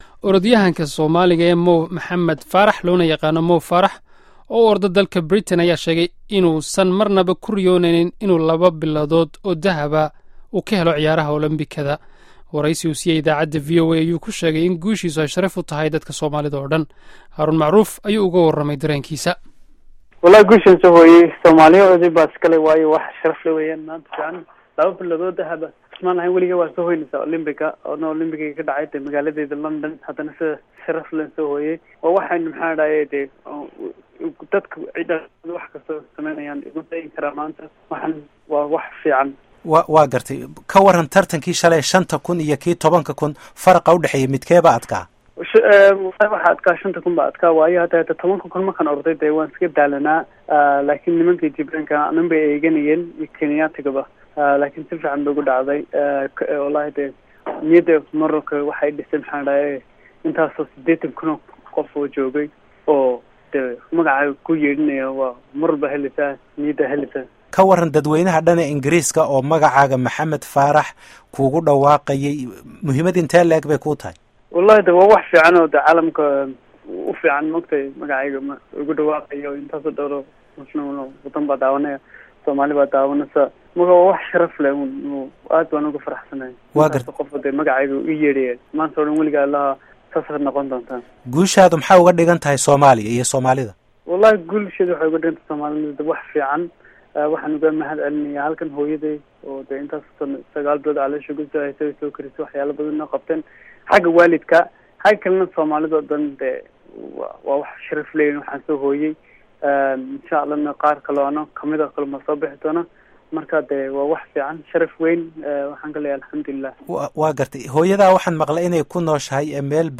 Wareysiga Mo Farah